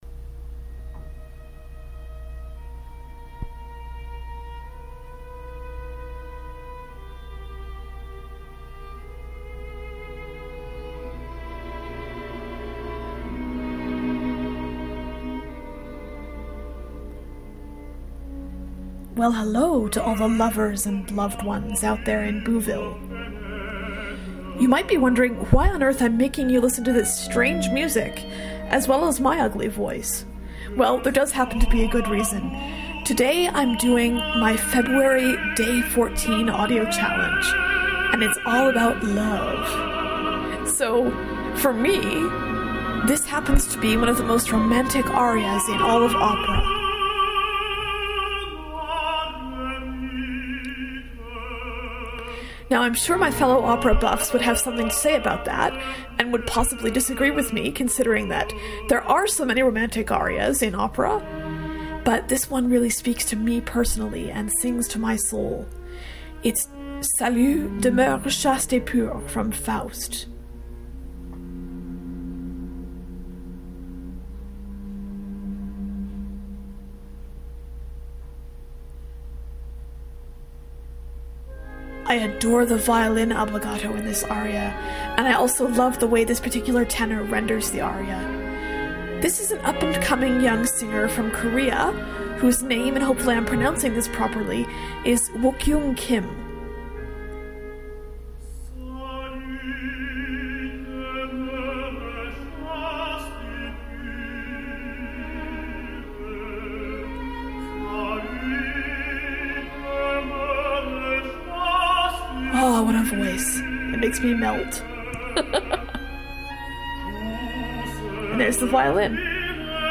Sorry for playing opera at the beginning of this Boo; I know that a lot of people don't enjoy it. But I had to play one of the most romantic arias I know.